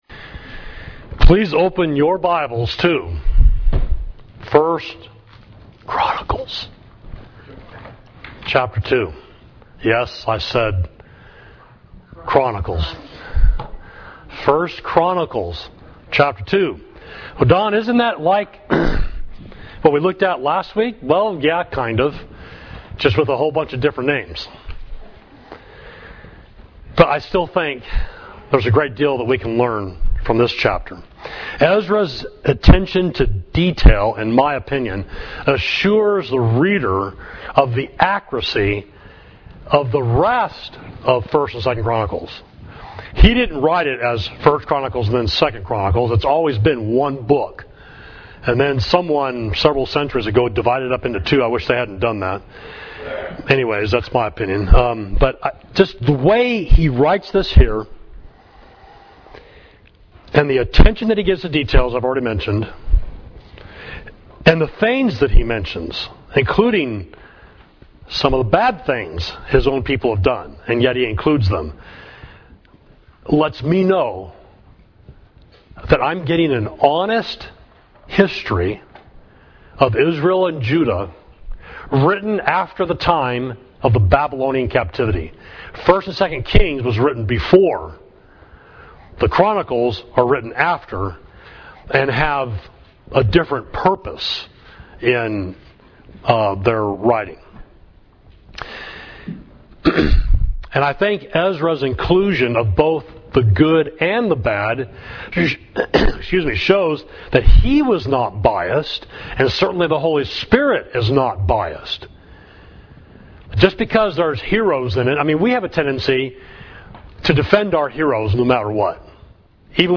Sermon: The Mighty Family Grows, 1 Chronicles 2